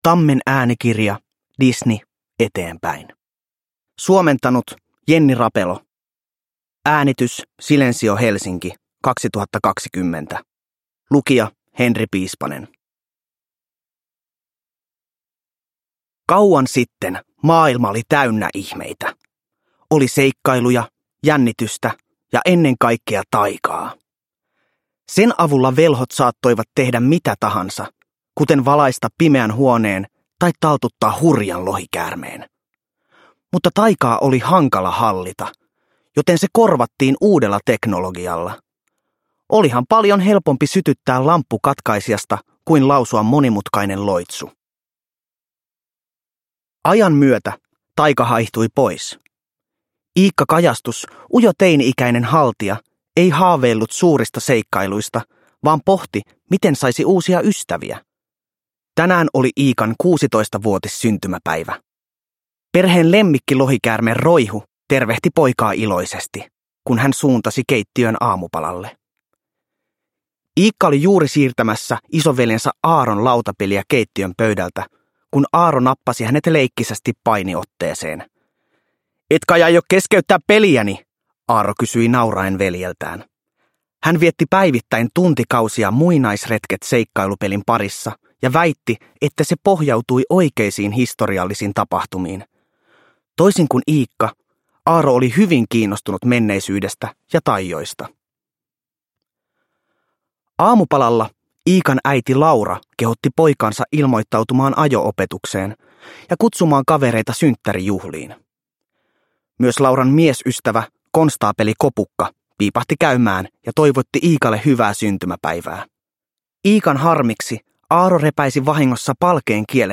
Disney. Eteenpäin Elokuvasuosikit – Ljudbok – Laddas ner